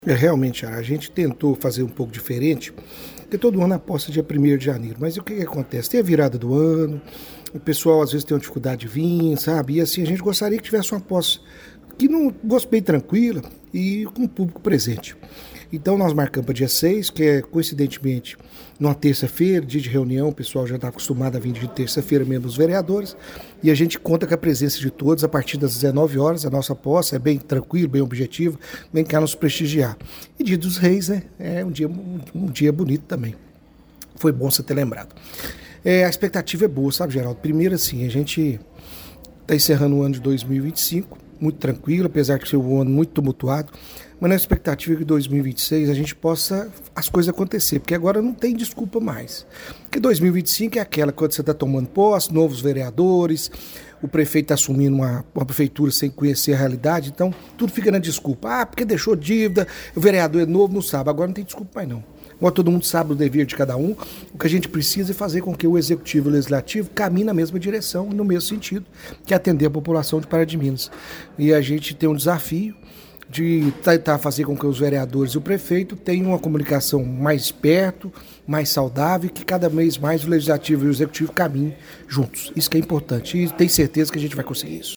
O presidente eleito falou que as expectativas são boas para o cago e aproveita para confirmar a data da posse da nova Mesa Diretora do Legislativo, marcada para 06 de janeiro de 2026: